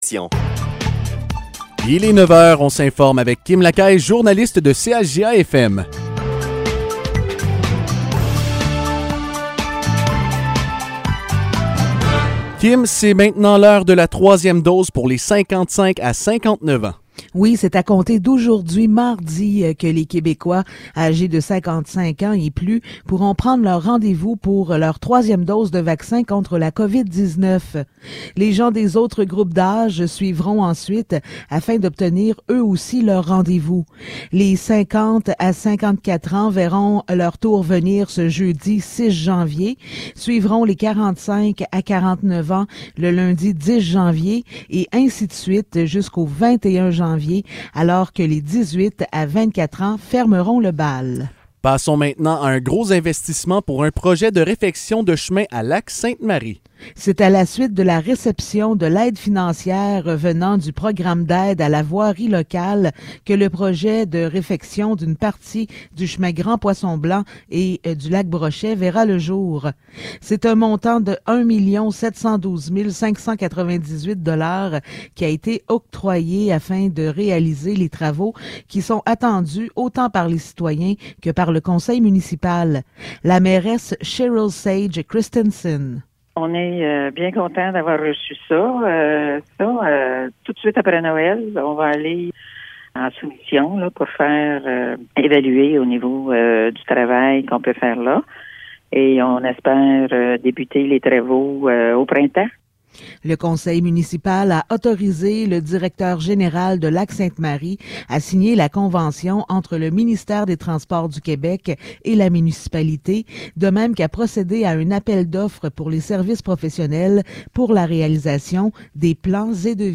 Nouvelles locales - 4 janvier 2022 - 9 h